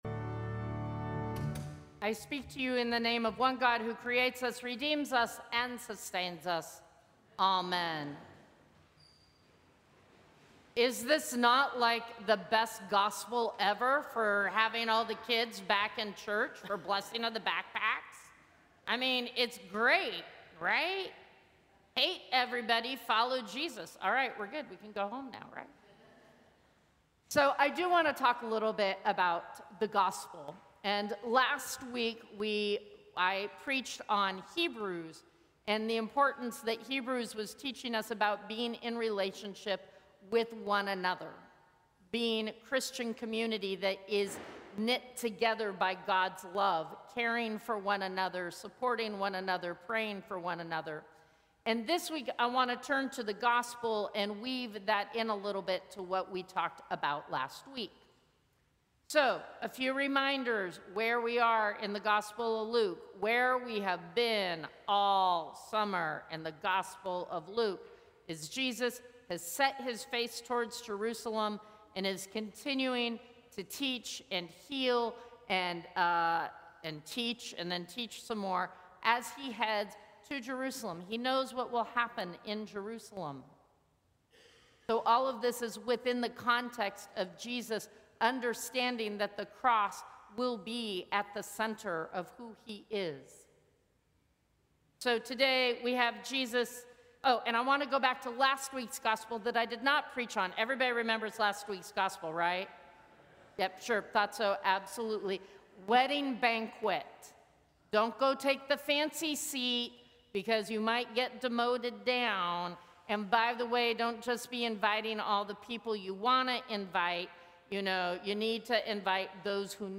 Sermons from St. Cross Episcopal Church Thirteenth Sunday after Pentecost Sep 07 2025 | 00:10:33 1x 00:00 / 00:10:33 Subscribe Share Apple Podcasts Spotify Overcast RSS Feed Share Link Embed